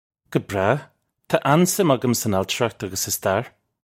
Guh brah. Tah an-sim uggum sun al-chir-ukht uggus sa star. (U)
This is an approximate phonetic pronunciation of the phrase.